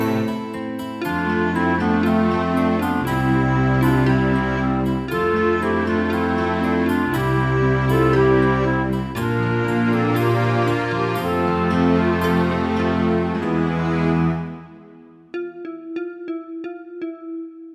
Down 1 Semitones Musicals 2:57 Buy £1.50